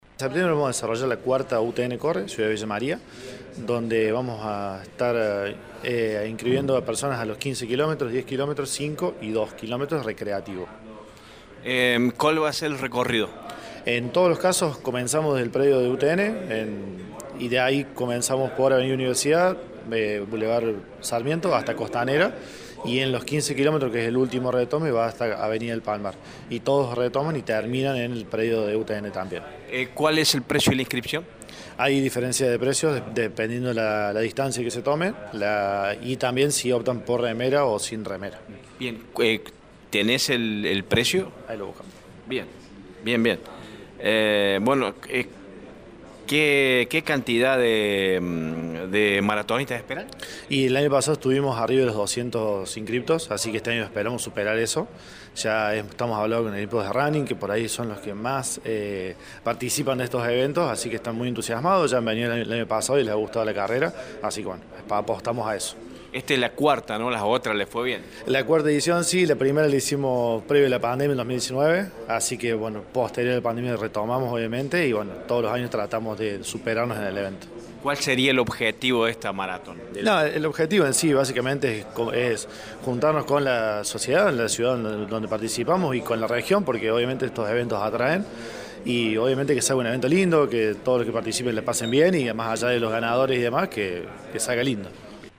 en diálogo con Radio Show